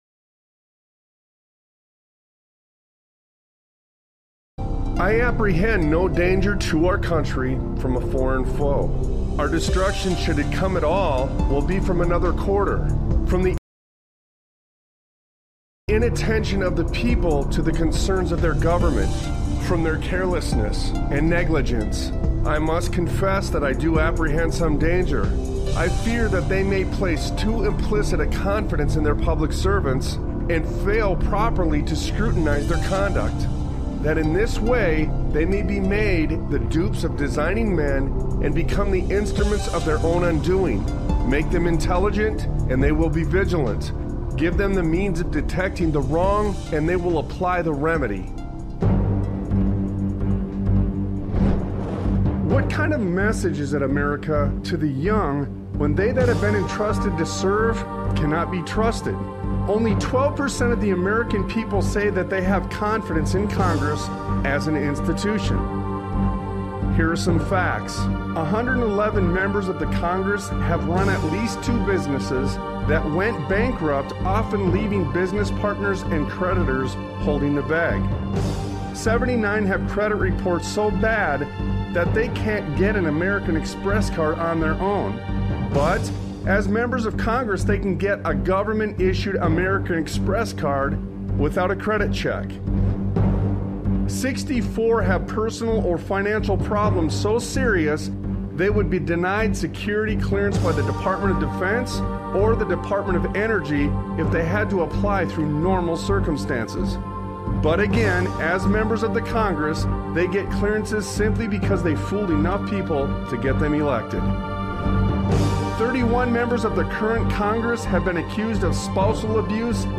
Talk Show Episode, Audio Podcast, Sons of Liberty Radio and Americans Are Teaching Criminals That They Can Get Away With This on , show guests , about Americans Are Teaching Criminals,That They Can Get Away With This, categorized as Education,History,Military,News,Politics & Government,Religion,Christianity,Society and Culture,Theory & Conspiracy